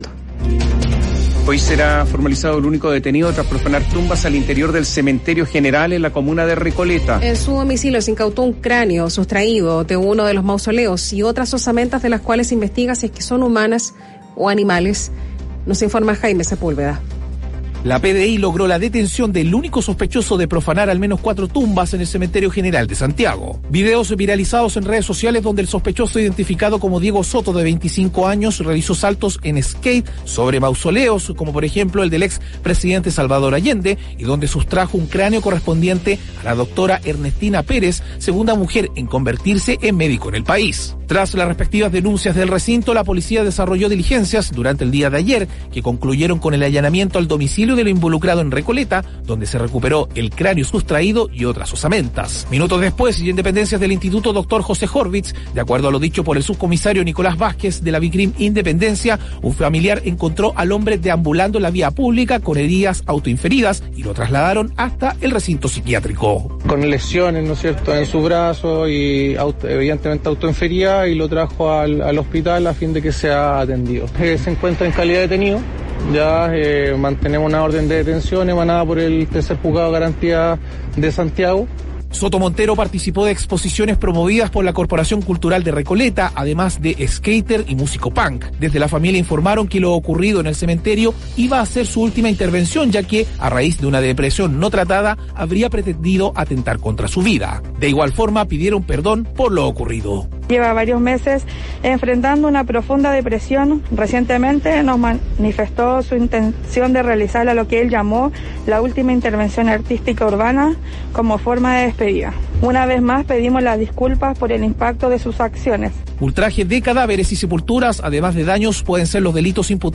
Puede oír la nota emitida por el noticiero matinal de Radio Bíobio a continuación.